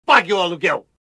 Categoria: Sons virais
Descrição: Áudio Senhor Barriga: Pague O Aluguel!" traz o clássico bordão do icônico personagem de Chaves.